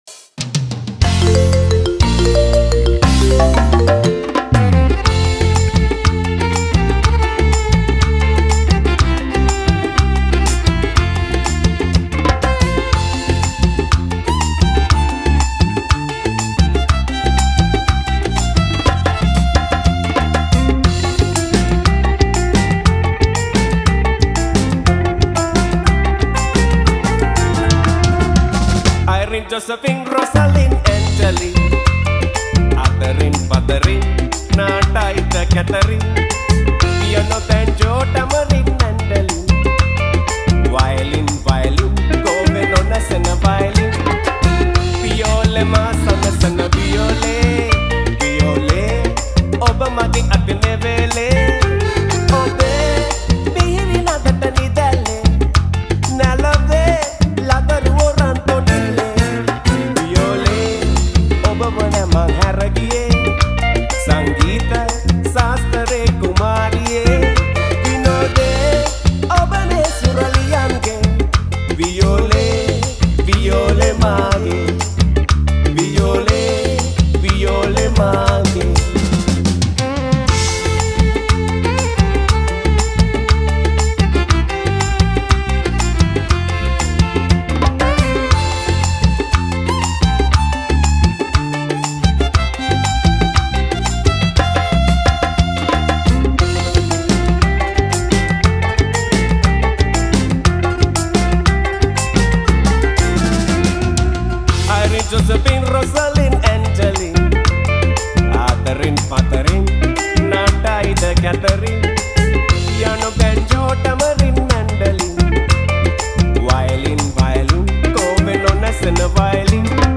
Releted Files Of Sinhala Band Medley Songs